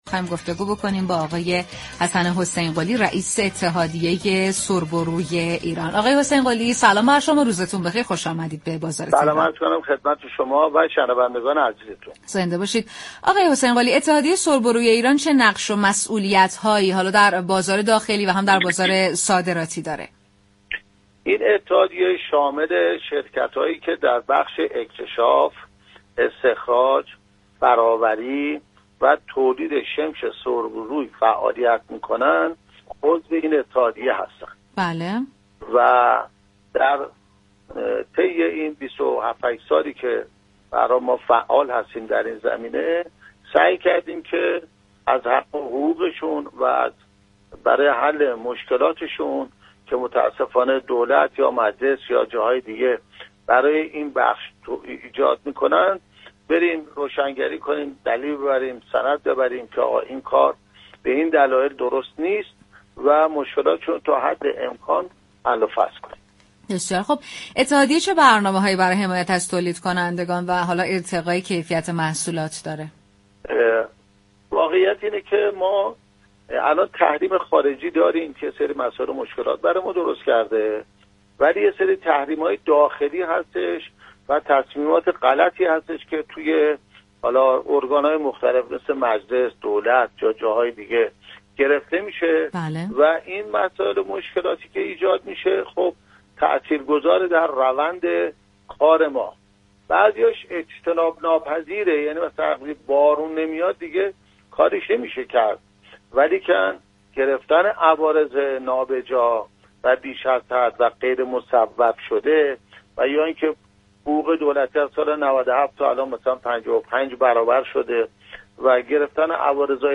در گفتگو با رادیو تهران